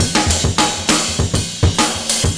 100CYMB10.wav